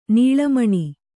♪ nīḷa maṇi